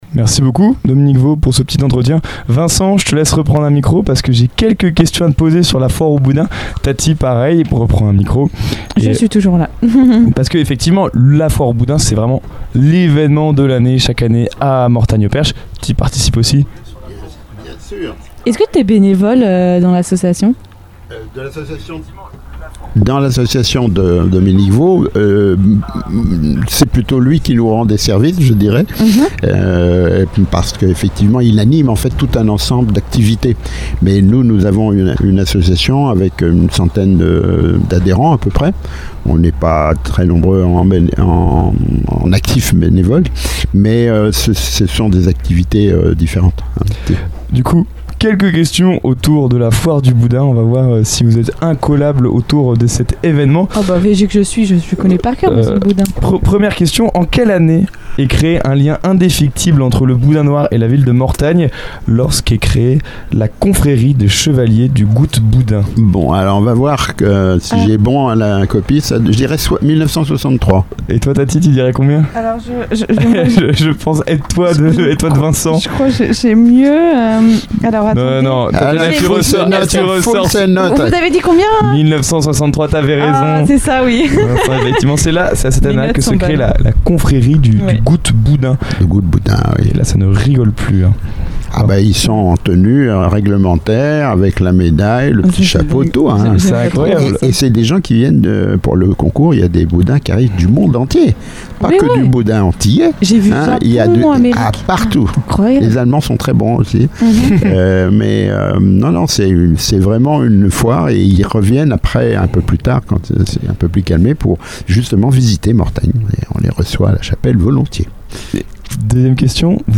Un échange passionné et engagé qui met en lumière la continuité du travail associatif et la volonté de transmettre l’âme de Mortagne-au-Perche aux générations futures.